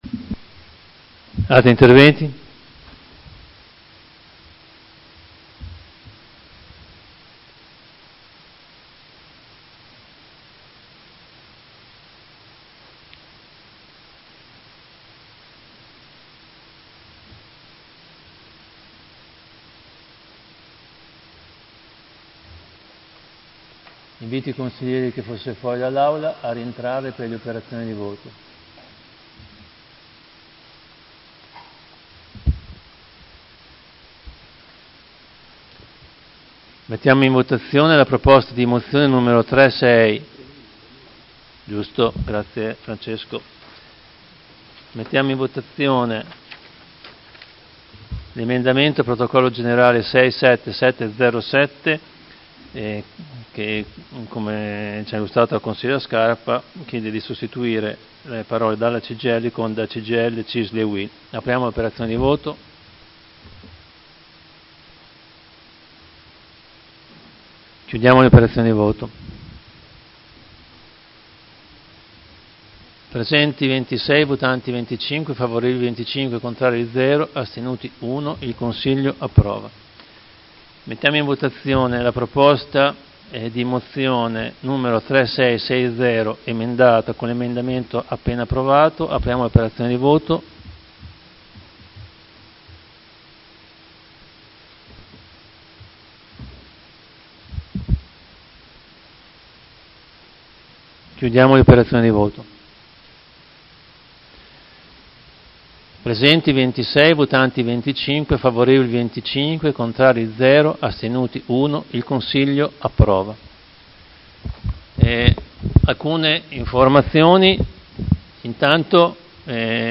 Fabio Poggi — Sito Audio Consiglio Comunale